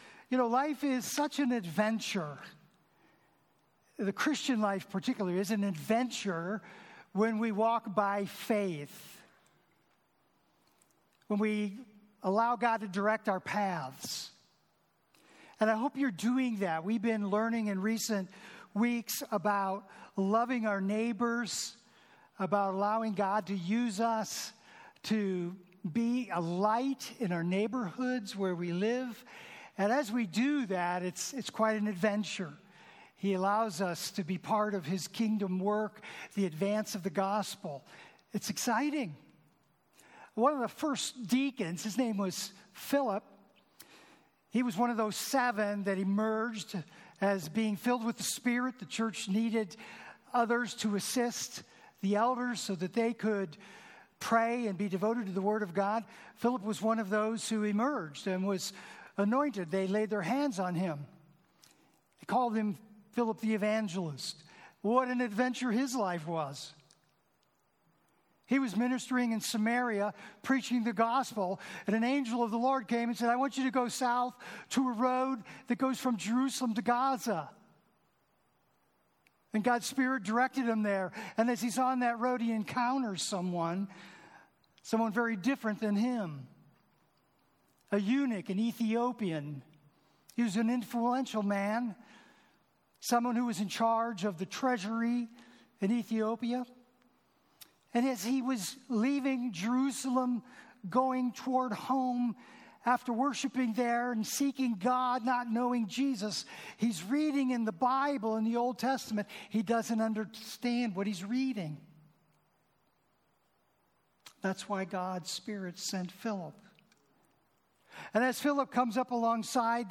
Home New Here Events About Our Beliefs Next Steps Meet our Team Ministries Bless App Kids Youth Women Men Senior Adults Prayer Give Contact Previous Sermons What's the Big Deal About Baptism?